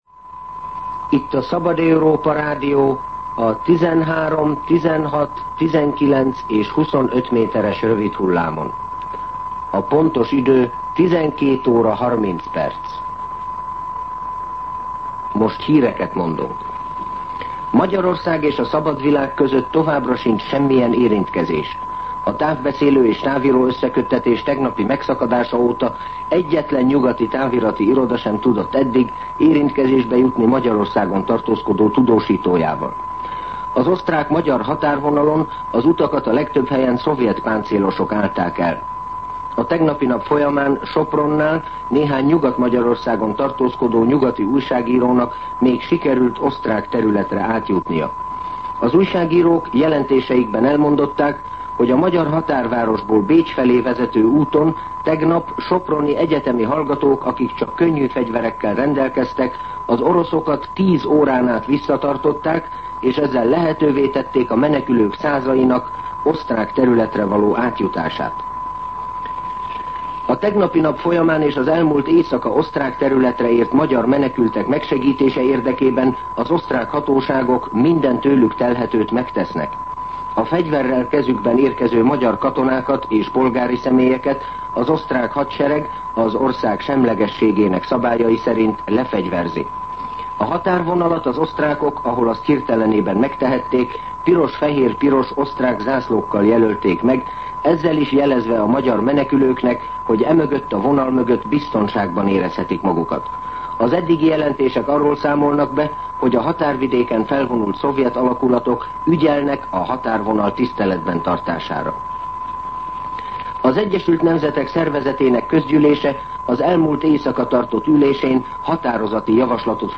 Hírszolgálat